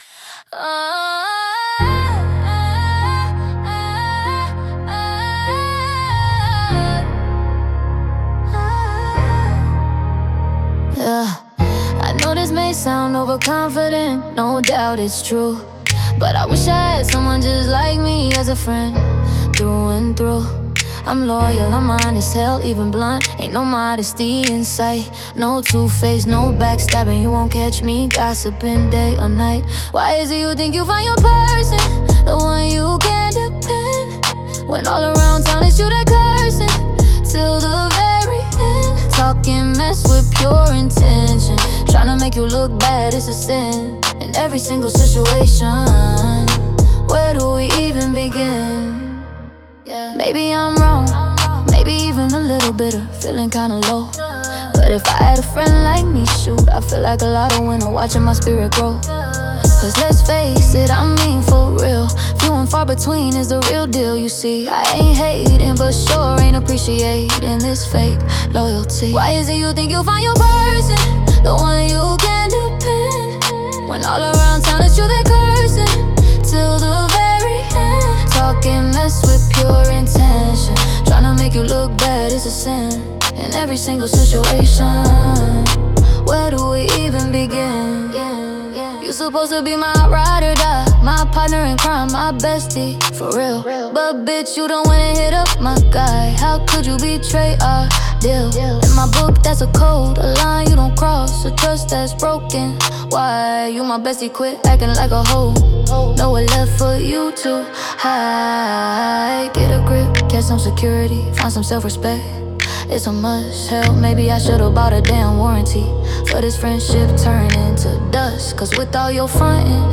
Strong, confident, and supportive.